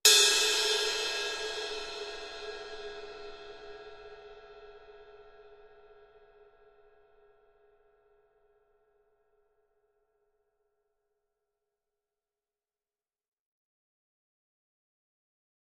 Cymbal, Medium, Single Hit, Type 1